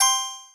Coins (9).wav